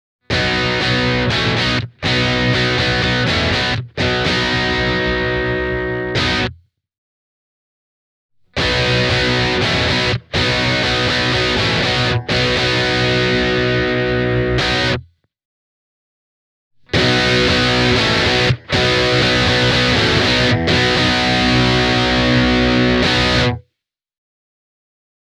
AmPlugin keskialue-boosterilta saa lisää tukevuutta ja säröä, silloin kun sitä tarvitaan. Klippi alkaa ilman boostausta, sitten tulee mieto lisäys, ja viimeisenä täysi mid-boosteri: